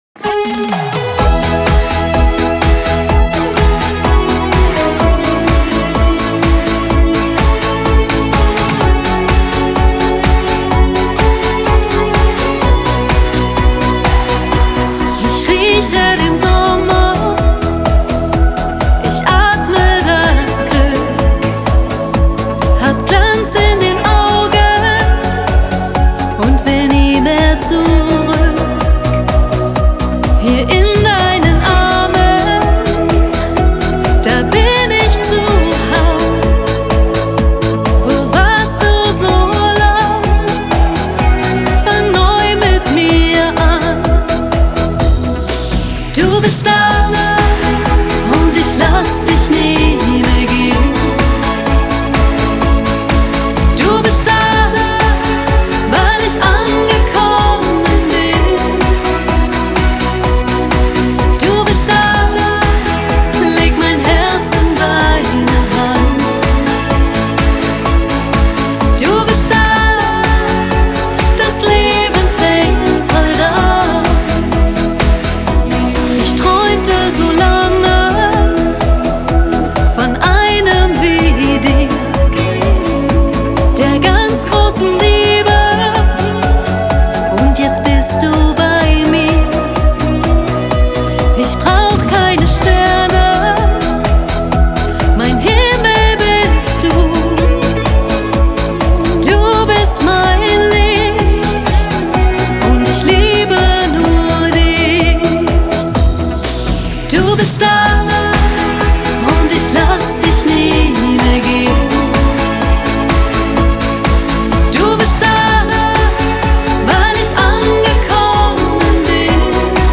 Жанр: Schlager
Genre: Schlager